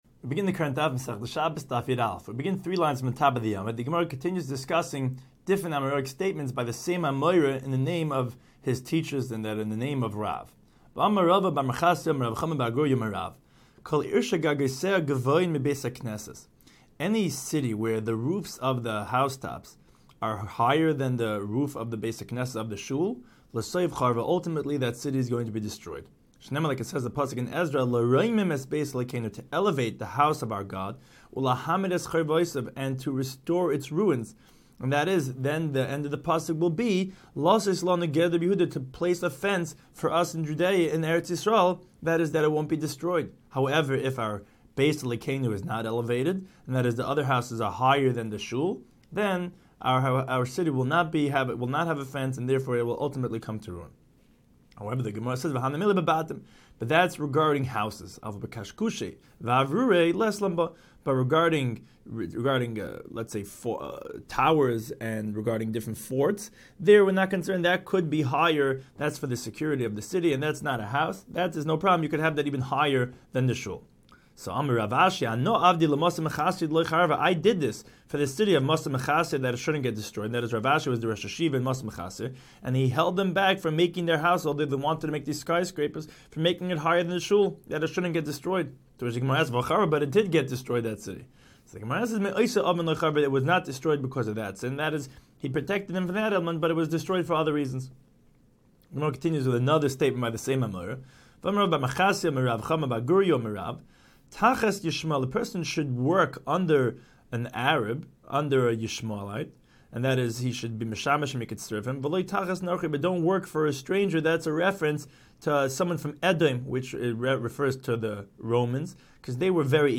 Daf Hachaim Shiur for Shabbos 11